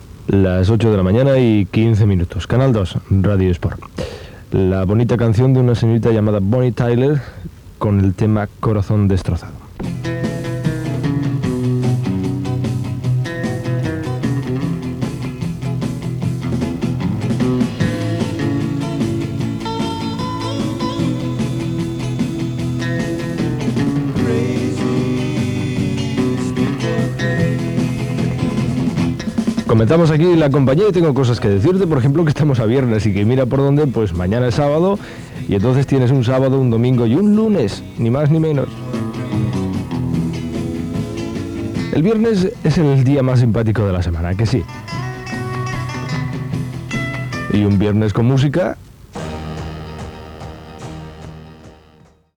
Hora, identificació de l'emissora i tema musical